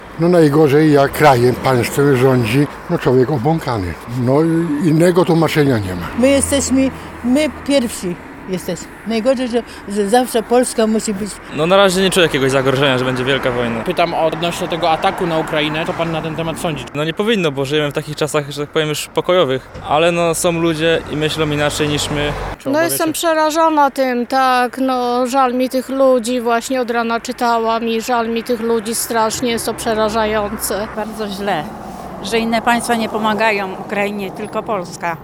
Zapytaliśmy mieszkańców jak oceniają konflikt na Ukrainie?: